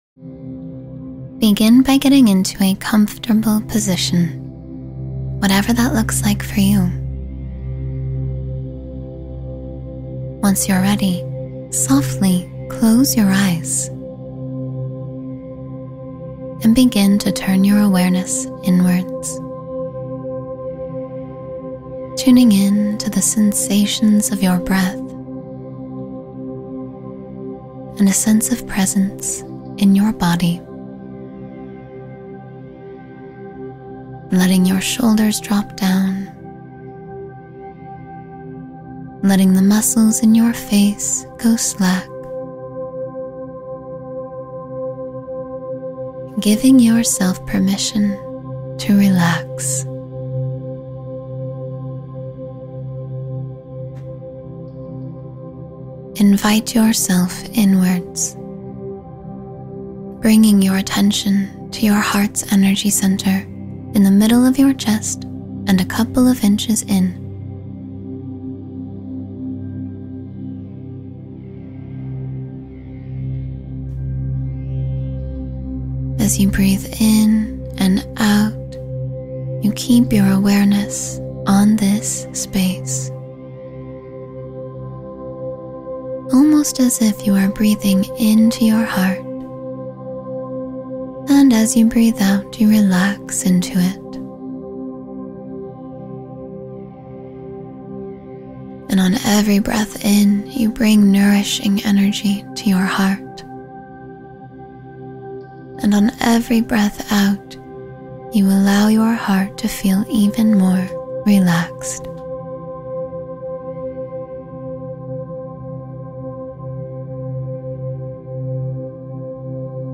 Sleep Peacefully and Enter Lucid Dreams — Guided Meditation for Restful Sleep